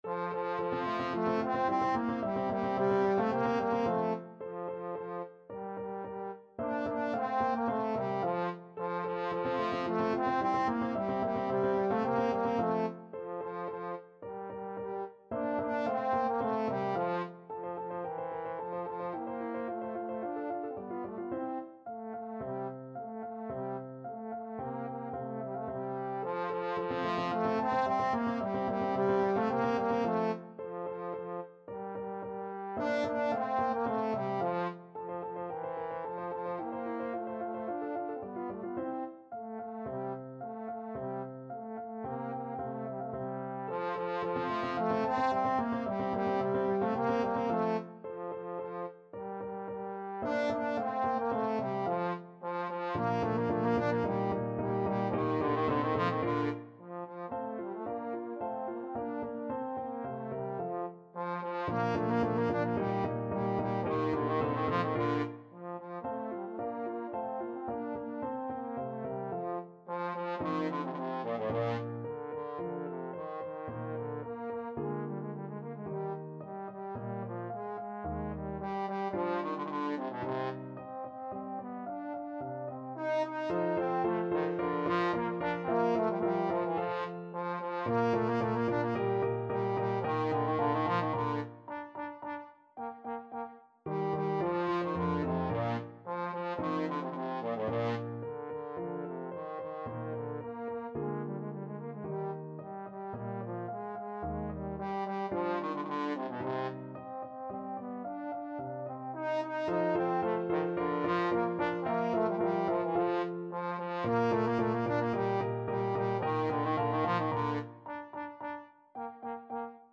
Trombone
2/4 (View more 2/4 Music)
A3-Eb5
F major (Sounding Pitch) (View more F major Music for Trombone )
Vivace assai =110 (View more music marked Vivace)
Classical (View more Classical Trombone Music)